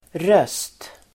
Uttal: [rös:t]